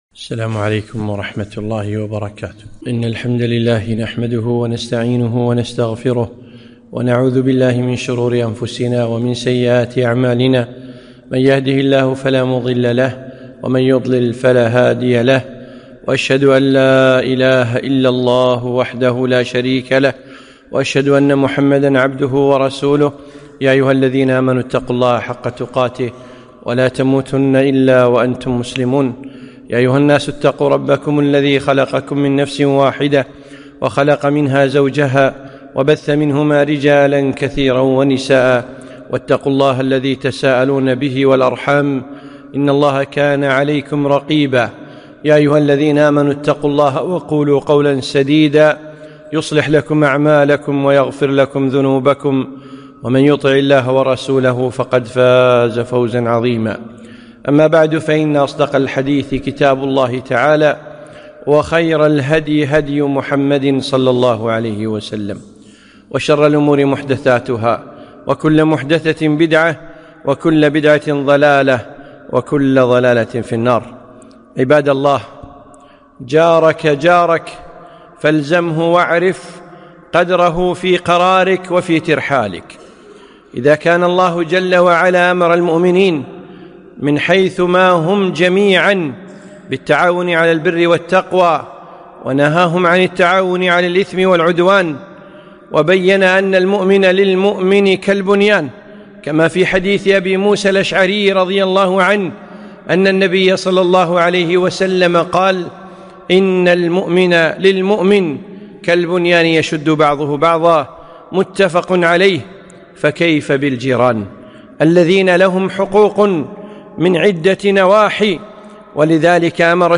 خطبة - جارك جارك